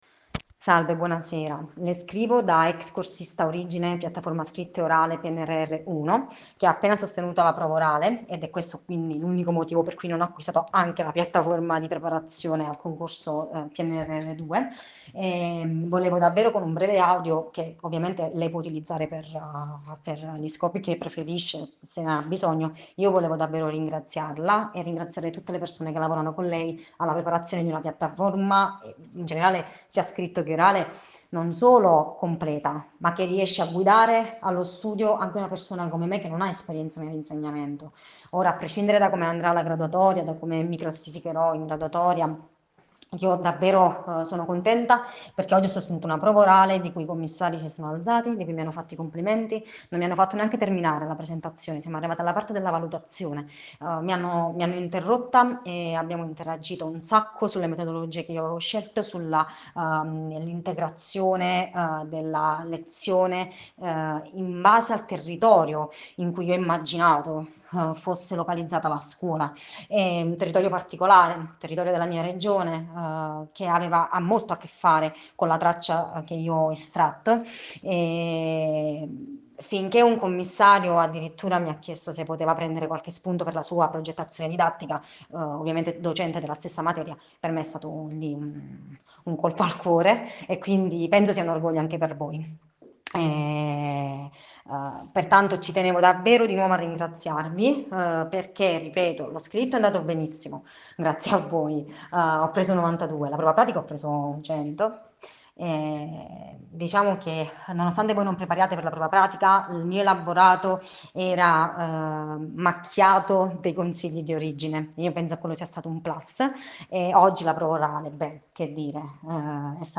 TESTIMONIANZA SOSTEGNO DA PARTE DI UNA NOSTRA UTENTE ASCOLTA